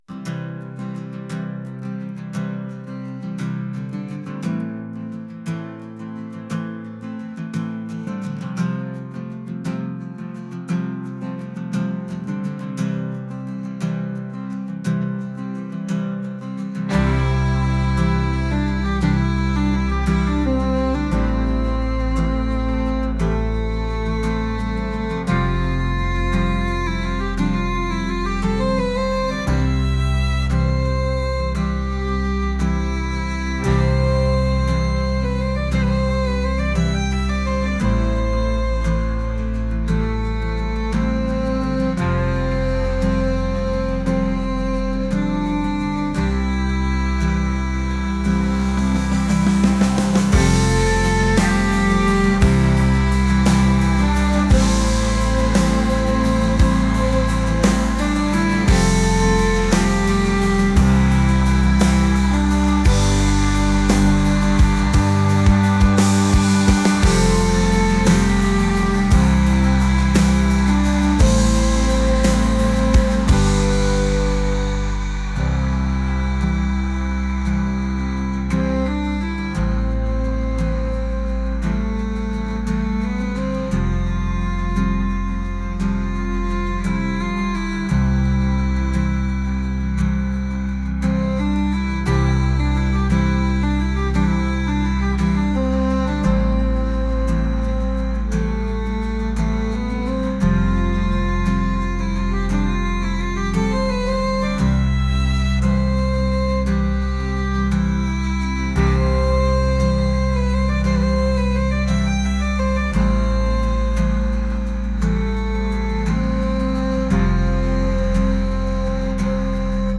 indie | acoustic | folk